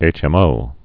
(āchĕm-ō)